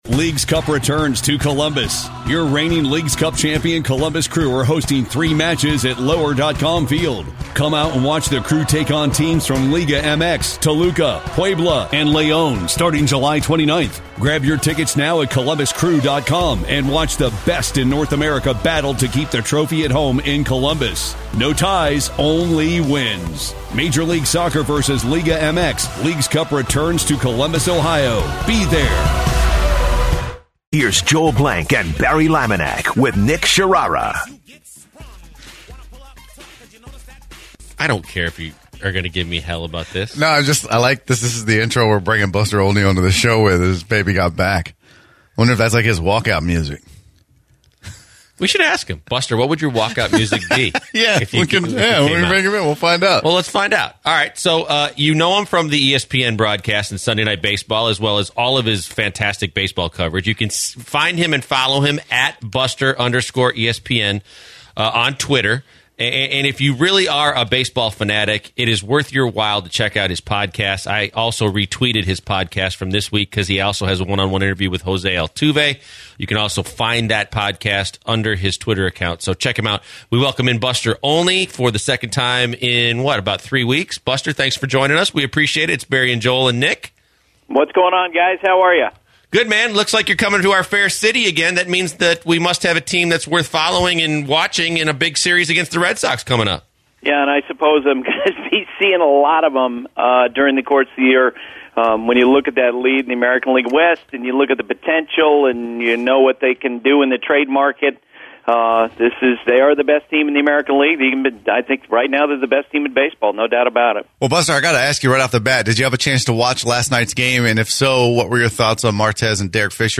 Buster Olney Interview